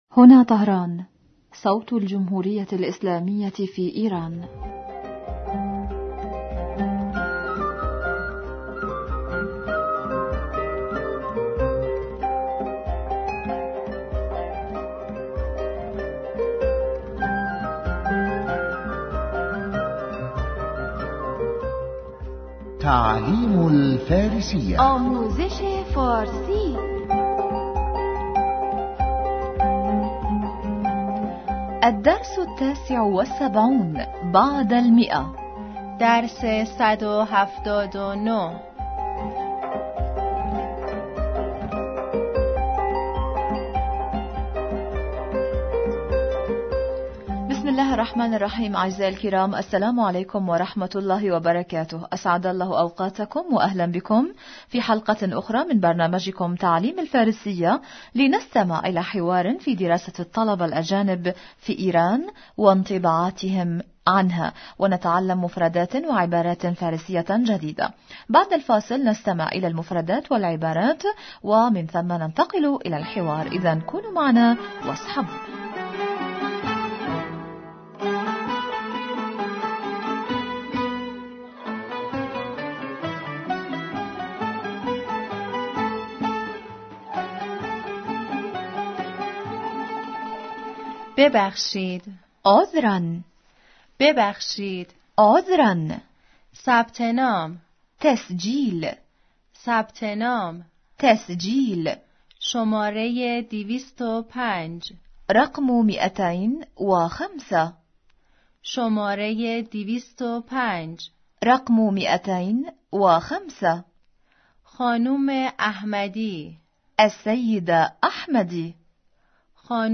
أسعد الله أوقاتكم و أهلاً بكم مستمعينا الكرام في حلقة أخرى من برنامجكم «تعلّم الفارسية» لنستمع إلى حوار في دراسة الطلبة الأجانب في ايران و انطباعاتهم عنها، و نتعلّم مفردات و عبارات فارسية جديدة.